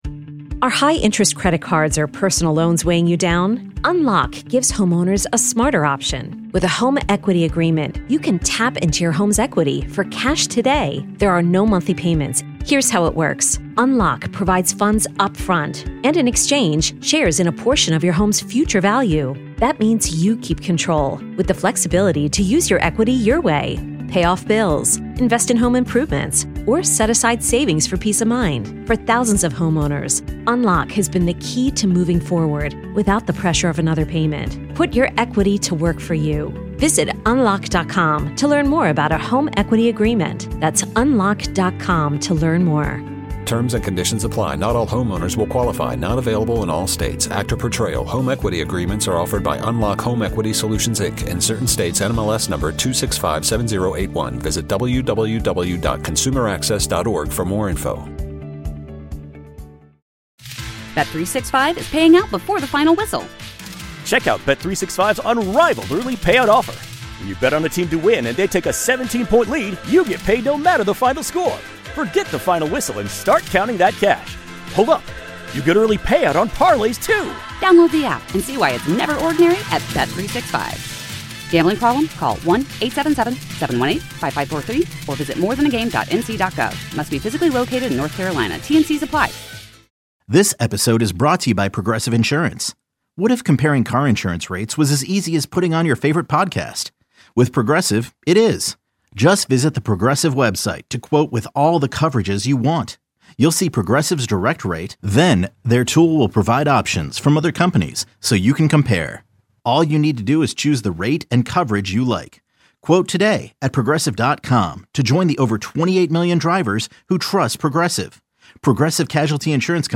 Hear more in the excerpt of the interview!Originally aired Decembter 4th, 3:51 PM